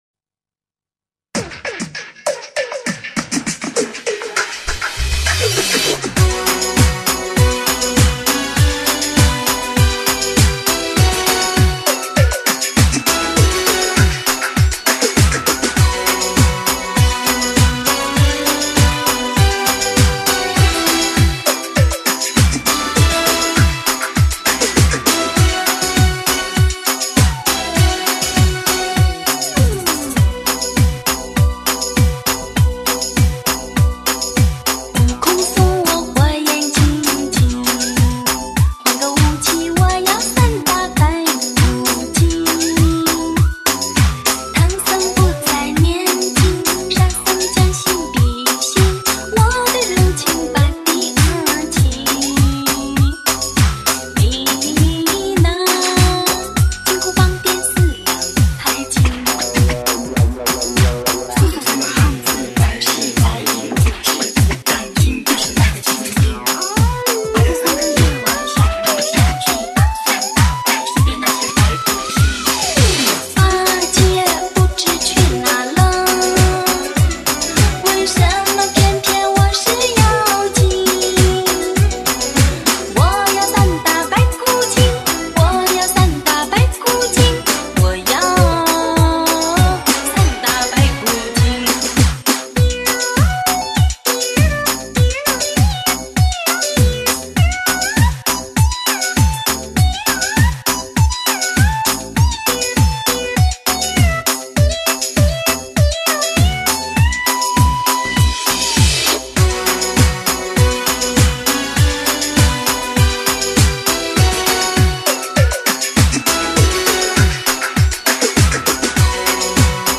绝对的HI-FI舞曲
无与伦比的劲爆节拍